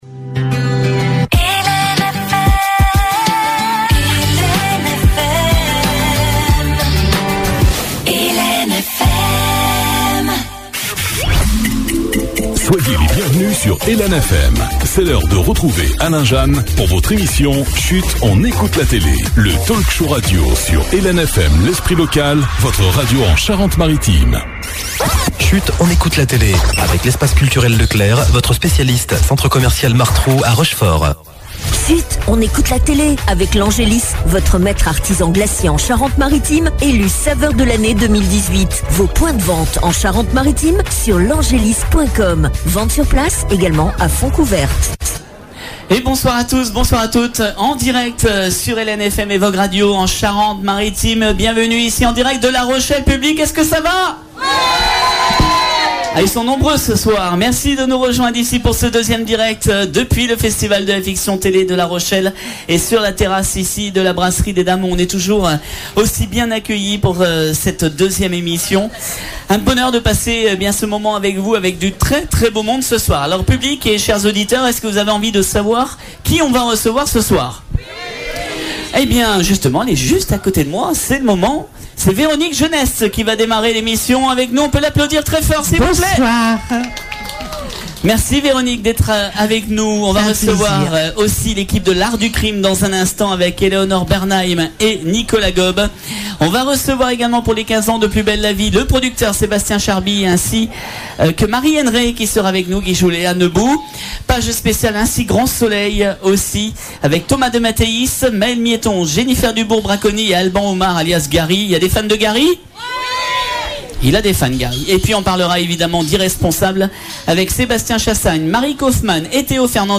Voici en réécoute nos émissions du festival de la rochelle réalisés sur la terrasse de la brasserie des dames que l’on remercie chaleureusement pour son accueil!